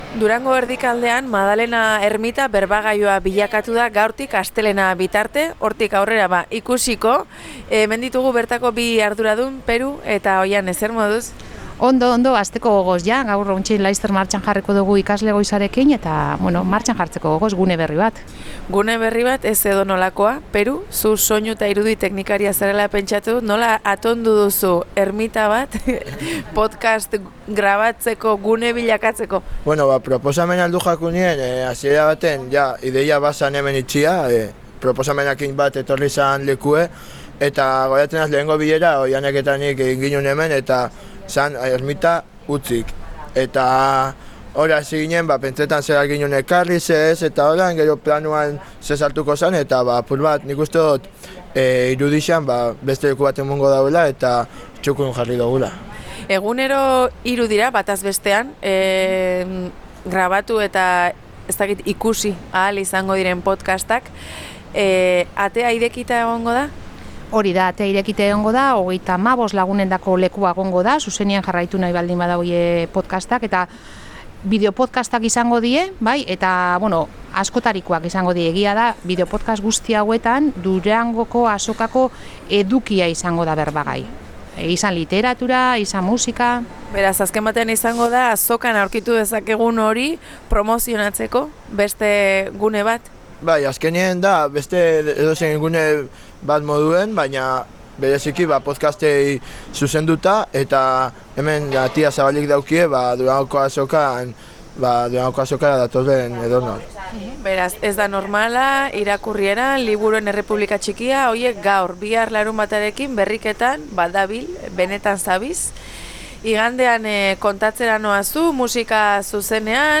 Gaurkoan Durangoko Azokatik Zebrabidea saio berezia izan dugu.